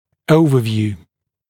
[‘əuvəvjuː][‘оувэвйу:]общее представление, общая картина; общий осмотр